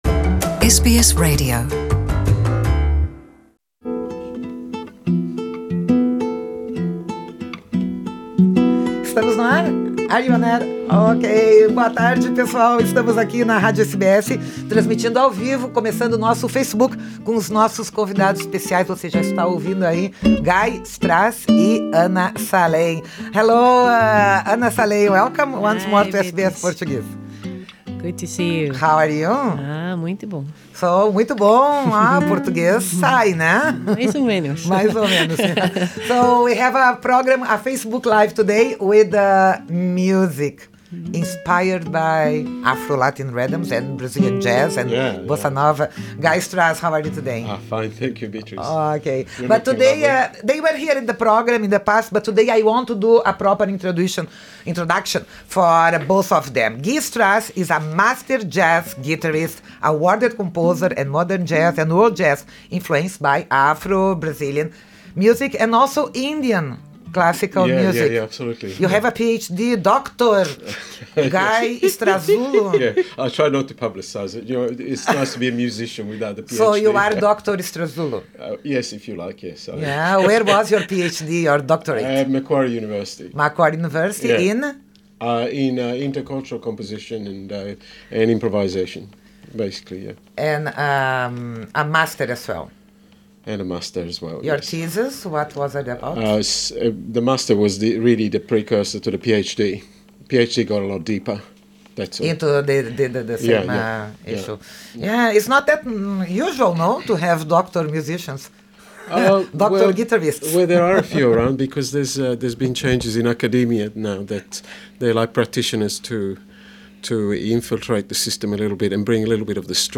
O dueto australiano tem influencias da Bossa Nova e da música afro-brasileira, com composições e músicas cantadas em inglês e em português.